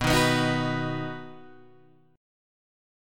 B Major